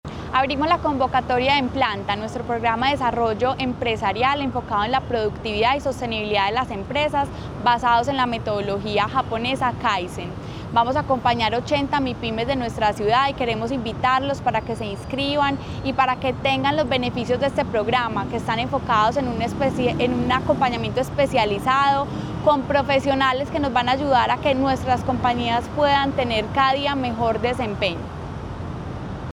Declaraciones, secretaria de Desarrollo Económico, María Fernanda Galeano Rojo.
Declaraciones-secretaria-de-Desarrollo-Economico-Maria-Fernanda-Galeano-Rojo..mp3